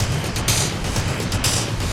RI_DelayStack_125-04.wav